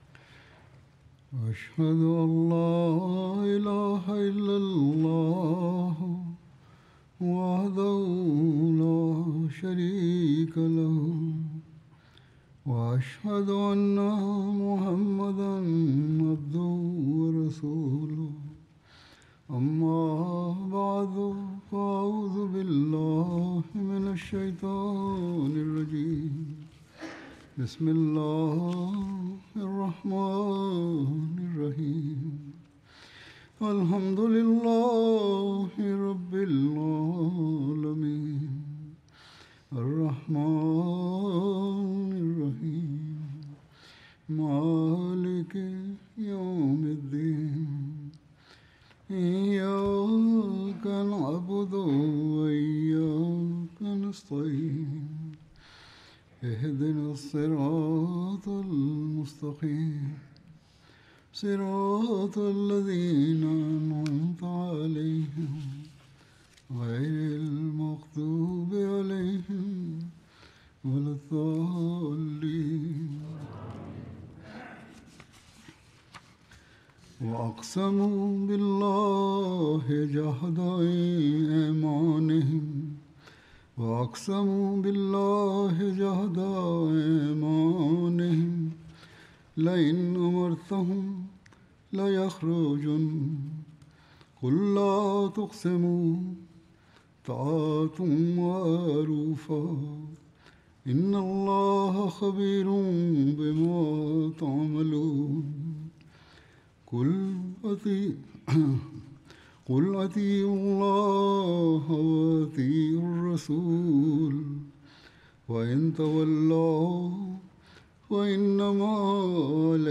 Russian Translation of Friday Sermon delivered by Khalifatul Masih